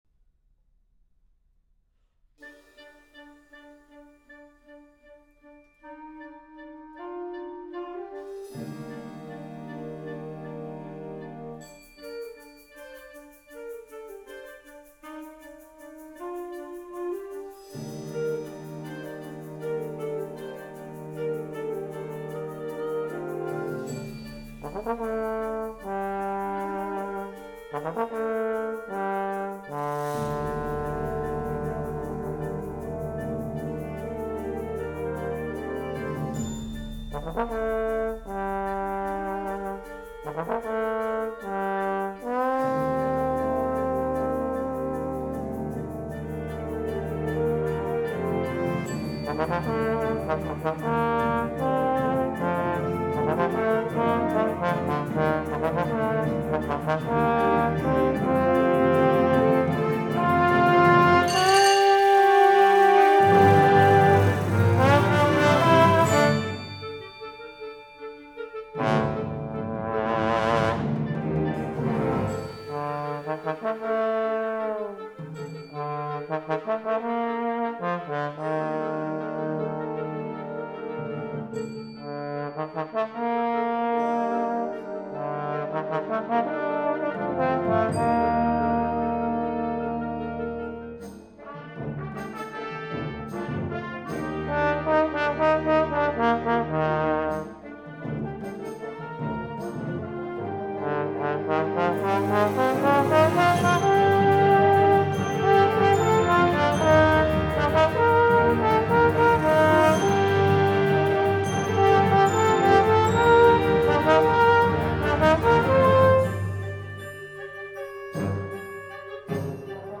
Voicing: Trombone w/ Band